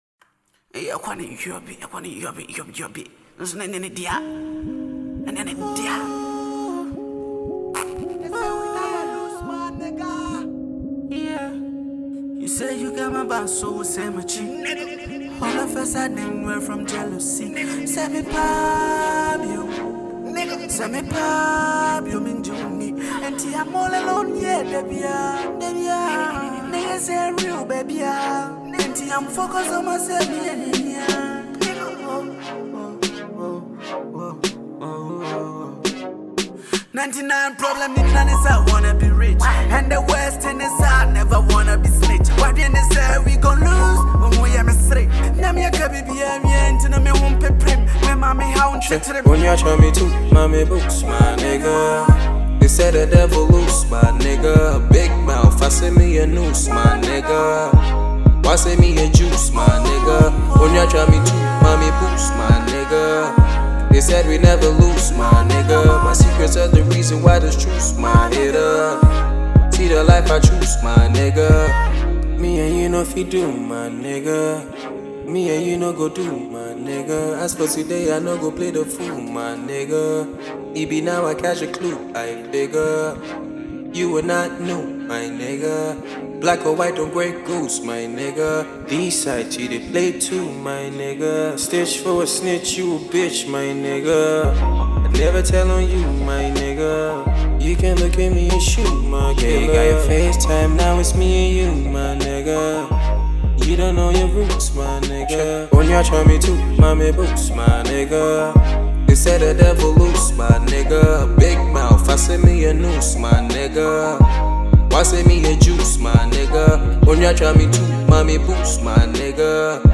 dope hiphop tune